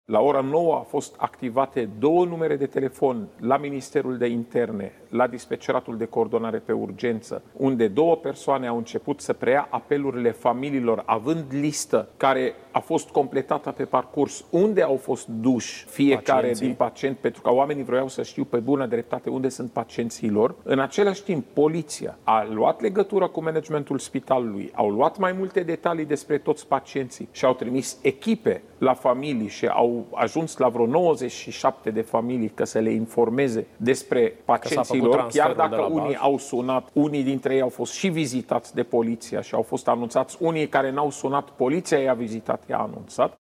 Raed Arafat, într-o declarație la Antena 3: